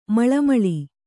♪ maḷa maḷi